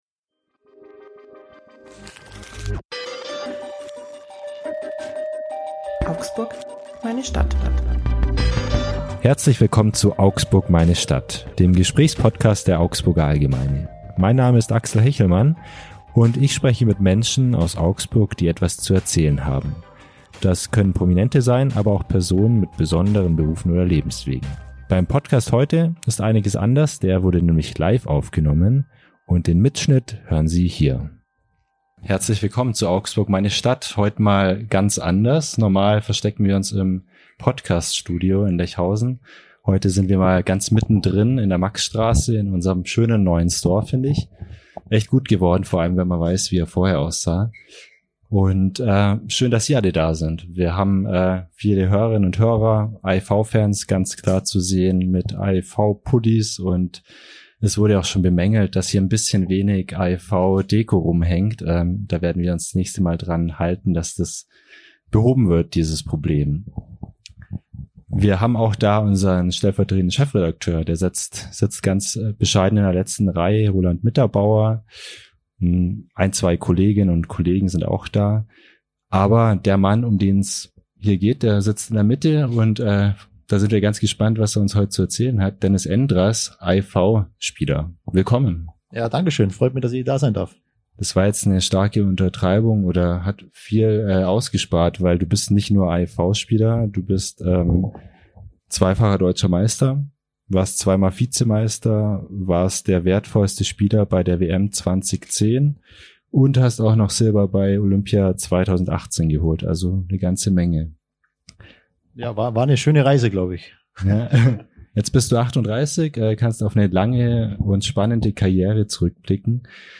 Jetzt spricht der 38-Jährige über seine Anfänge auf dem Eis, seine größten Erfolge und die Zeit nach der Karriere. Für die Folge hat unsere Redaktion den Podcast "Augsburg, meine Stadt" vom Aufnahmestudio auf die Bühne verlagert. Das Gespräch mit Endras ist das erste von dreien, das vor einem kleinen Publikum im neu gestalteten Kundencenter der Augsburger Allgemeinen in der Maximilianstraße 3 stattfand.